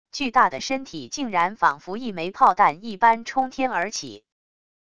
巨大的身体竟然仿佛一枚炮弹一般冲天而起wav音频生成系统WAV Audio Player